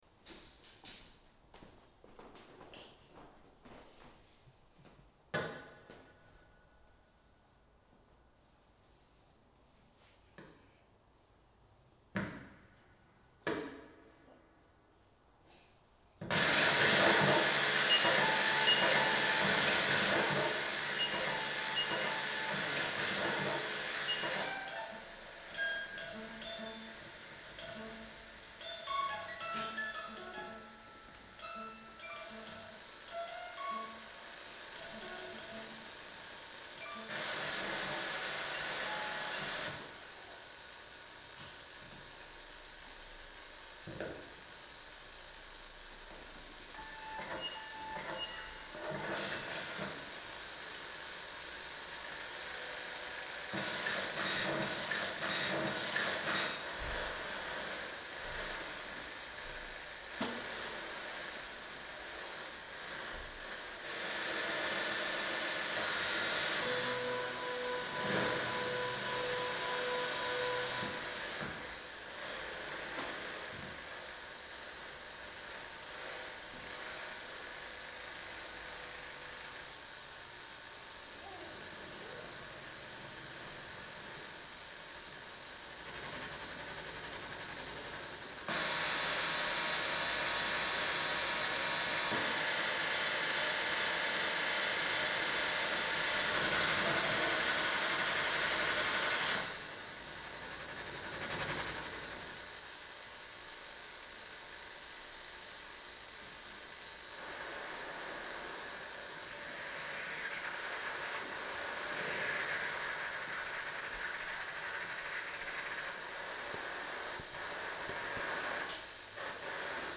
Live Improvisation: The Big Room, New Haven CT, April 24 2010
This performance took place on April 24th 2010 in New Haven CT at The Big Room. Our setup consisted of a number of circuit-bent toys and original electronic instruments, hardware mixers and computers running SuperCollider programs of our own creation.